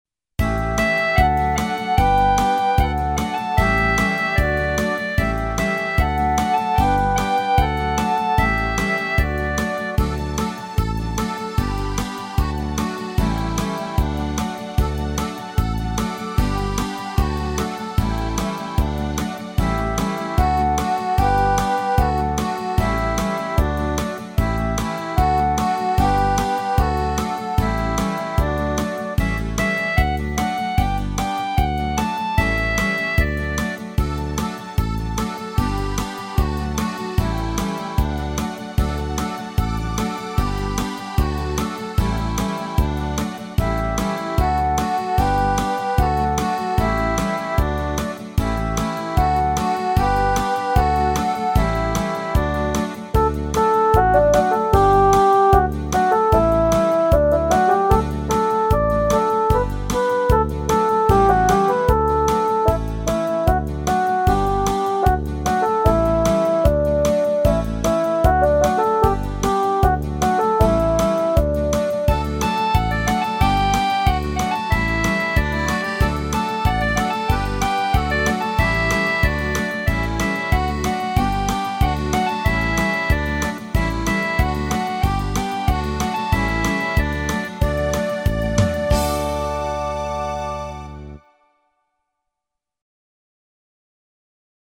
Украинская народная мелодия.